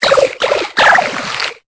Cri de Colimucus dans Pokémon Épée et Bouclier.